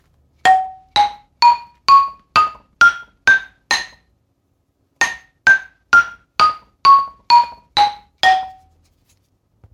ミニ バラフォン ブルキナファソ製 8音 アフリカの打楽器 （p360-06） - アフリカ雑貨店 アフロモード
あまり音量が大きくないので家で気軽に楽しんで叩けます。
工業製品と違い響きや音階にバラツキがあり完璧なものはありません。
説明 この楽器のサンプル音 原産国 材質 木、ひょうたん、革、ヒモ サイズ 長さ29cm 最大幅23cm 高さ：11cm 重量 540g コメント ※写真の商品をお届けします。